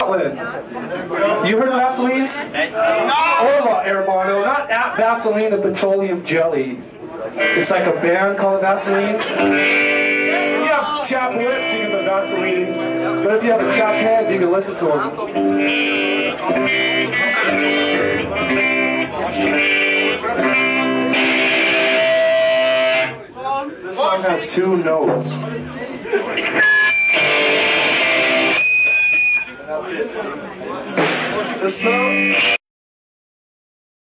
More talking (656 KB) - Linz show